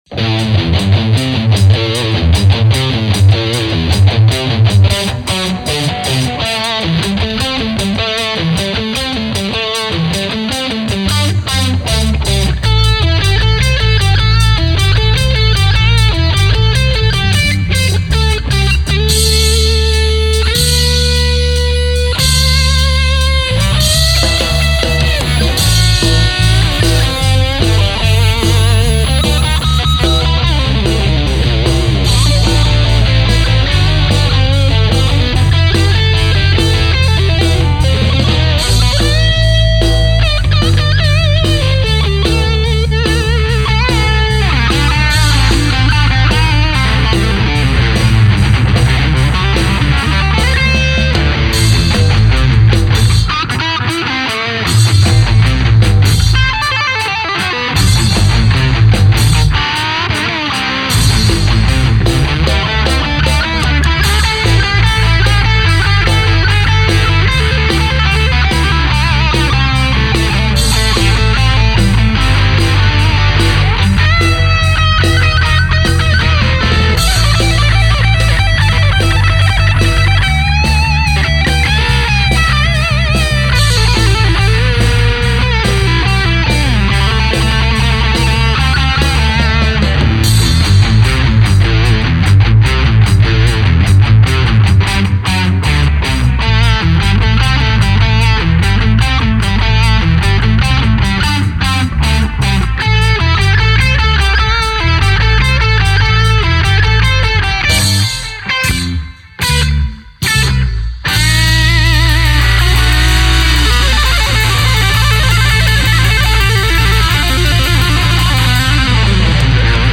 Потому не судите строго-это не студийные вылизанные записи-это как в жизни- по всякому.